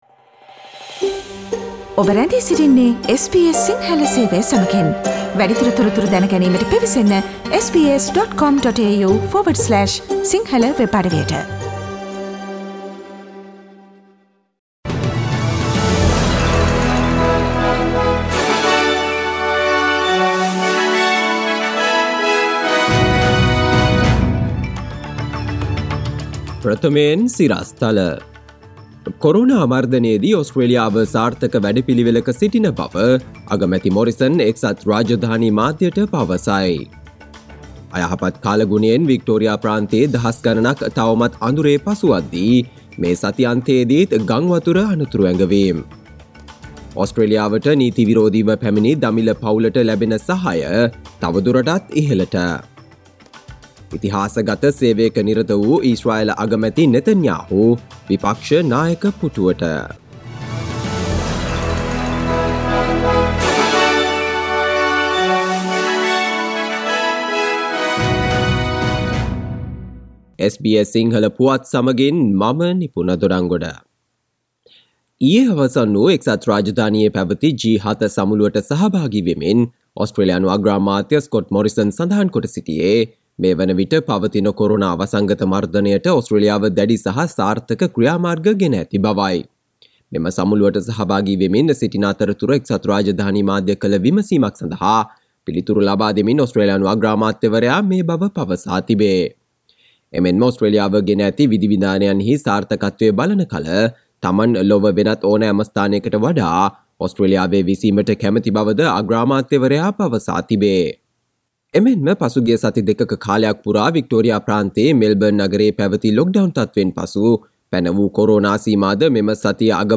Here are the most prominent Australian and World news highlights from SBS Sinhala radio daily news bulletin on Monday 14 June 2021.